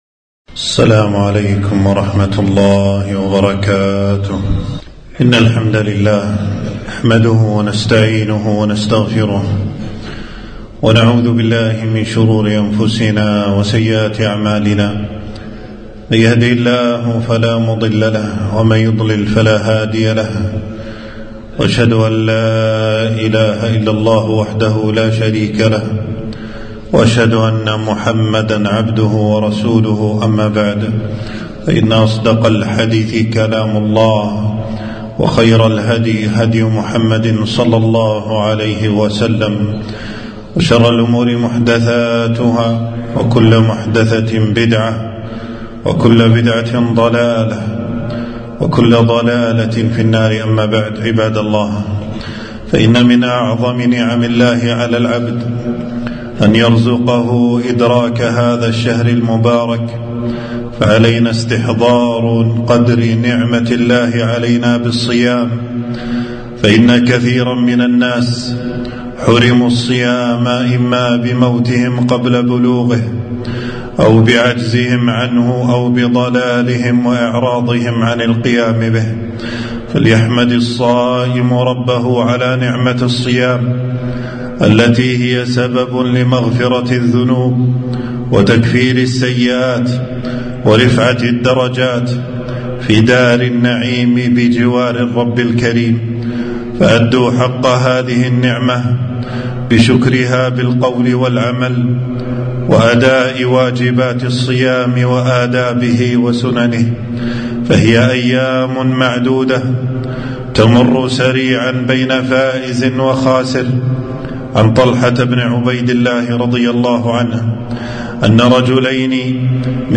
خطبة - تنبيه المسلمين لأحكام الصائمين